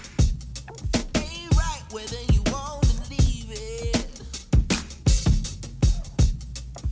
【ミックスのパーカッションを調整】
drums-too-loud-vocals-too-quiet.mp3